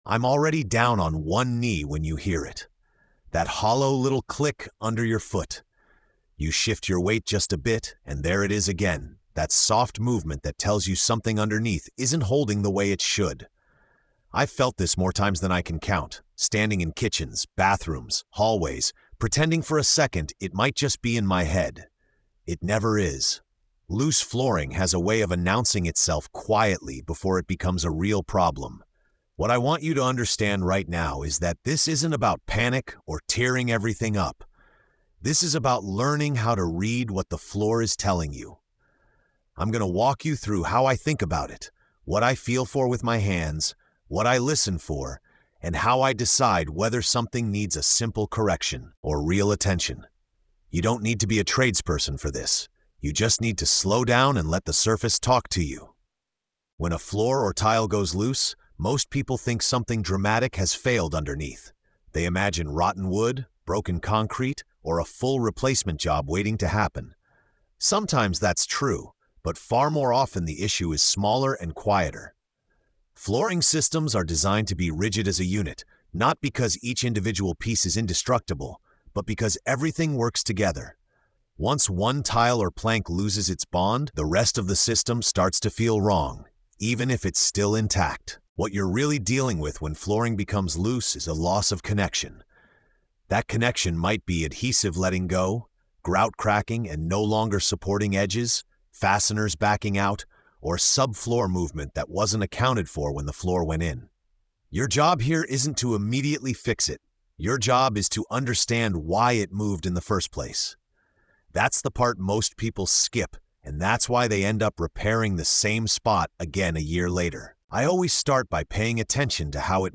Told from the perspective of an experienced tradesman working in real time, this episode focuses on feel, judgment, and patience rather than quick fixes. The listener is guided through the mental process professionals use to diagnose loose flooring, prepare the space correctly, and execute a repair that lasts. The tone is steady, reassuring, and grounded in real-world experience, emphasizing confidence over force and understanding over shortcuts.